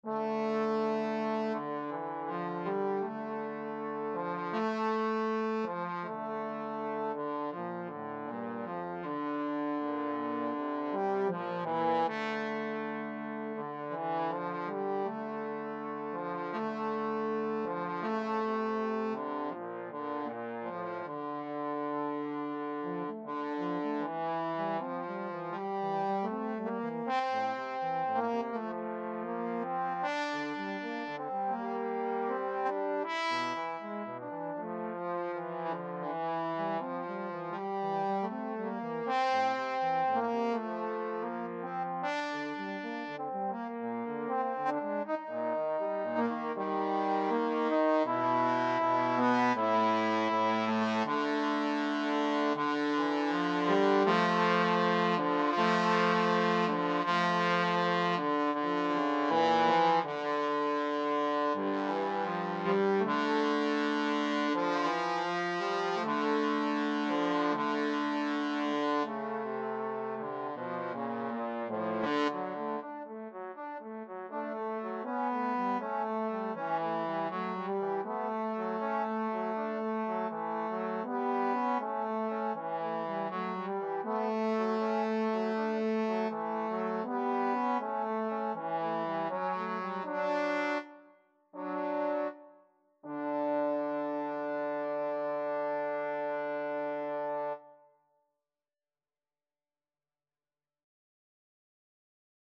Free Sheet music for Trombone Duet
D minor (Sounding Pitch) (View more D minor Music for Trombone Duet )
Andante = 80
4/4 (View more 4/4 Music)
Classical (View more Classical Trombone Duet Music)